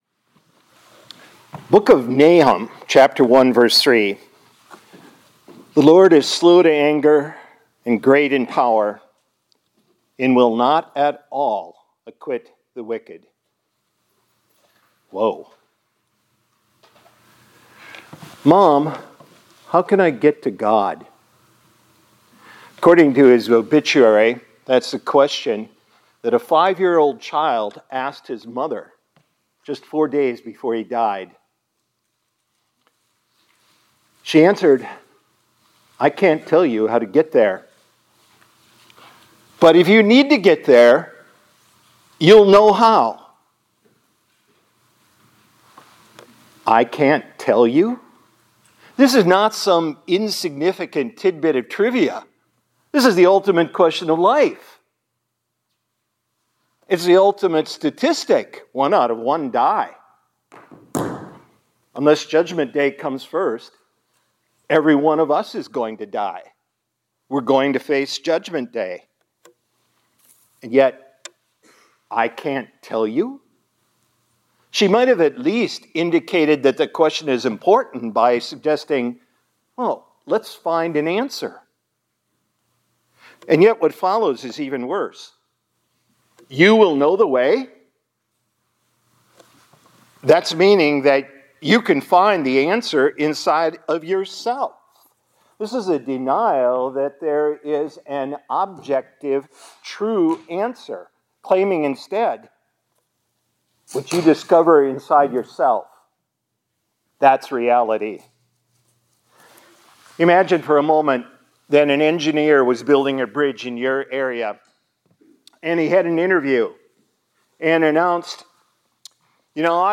2025-02-10 ILC Chapel — The Lord Will By No Means Acquit the Wicked